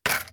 hook.ogg